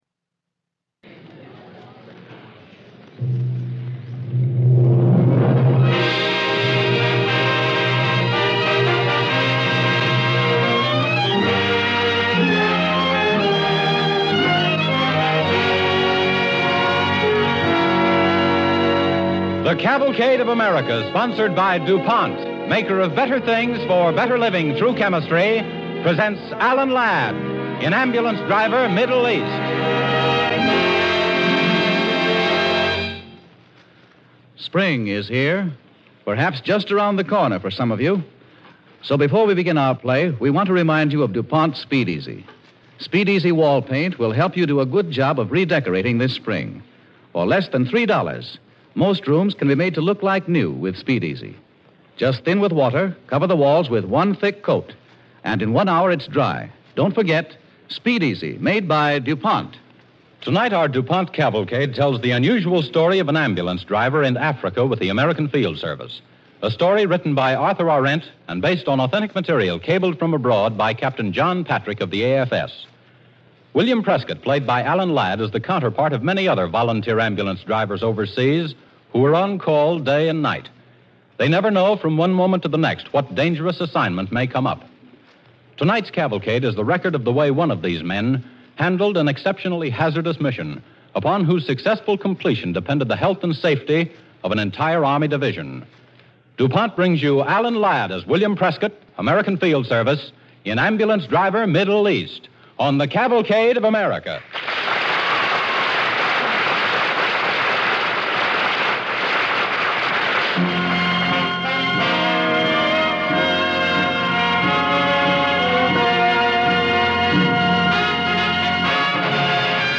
starring Alan Ladd